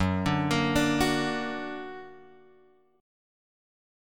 F#+ chord {2 1 0 x 3 2} chord